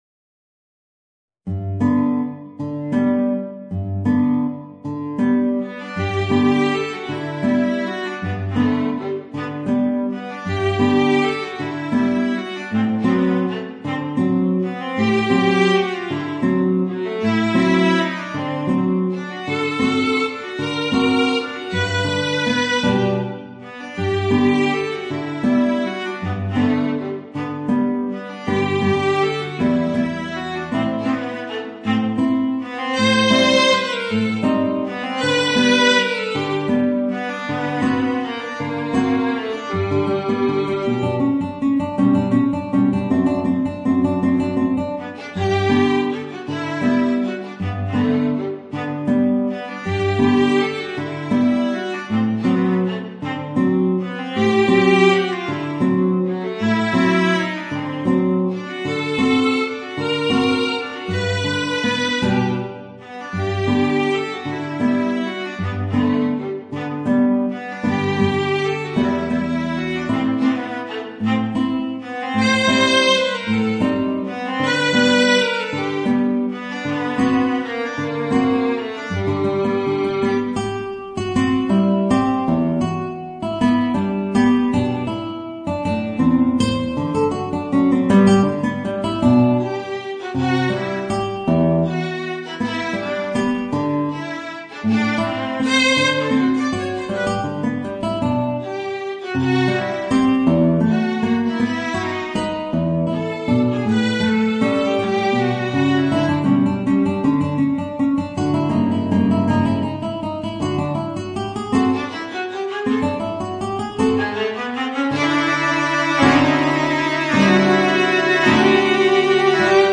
Voicing: Guitar and Viola